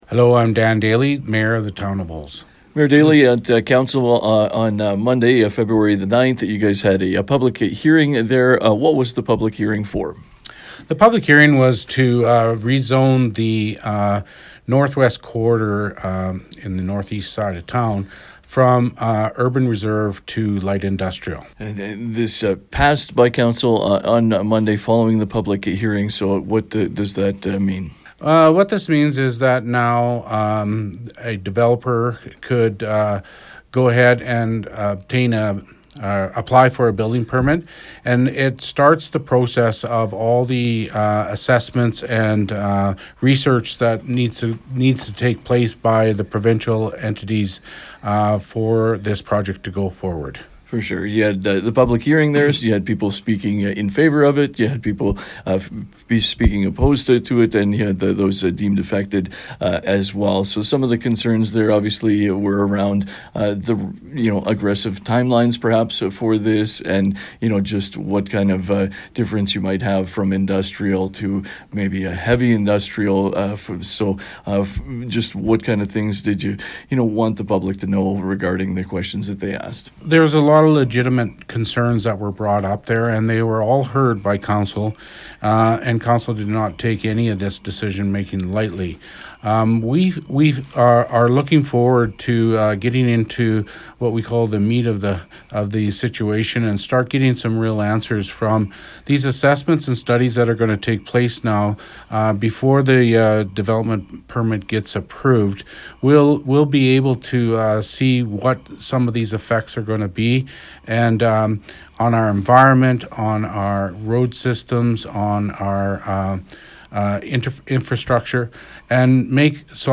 Feb10-Olds-Mayor-Dan-Daley.mp3